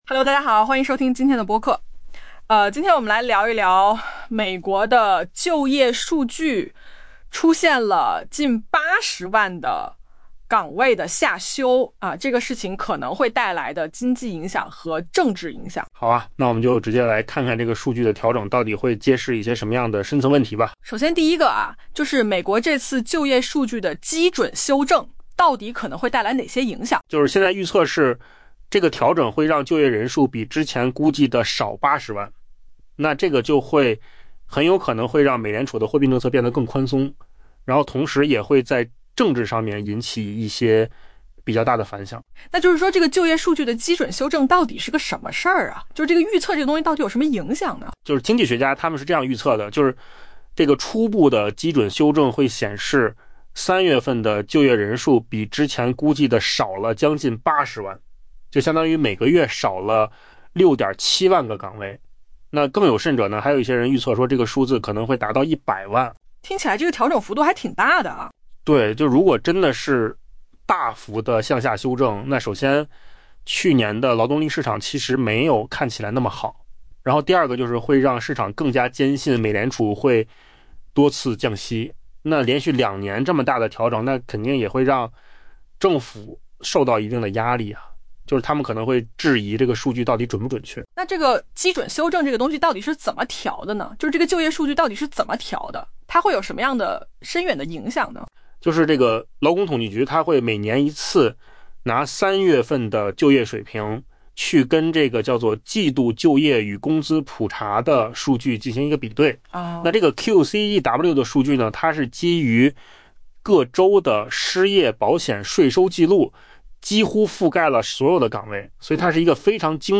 AI 播客：换个方式听新闻 下载 mp3 音频由扣子空间生成 在截至今年 3 月的一年里，美国就业增长可能远不如目前政府数据显示的强劲，这突显出，早在今年夏季招聘放缓之前，美国劳动力市场就已进入放缓阶段。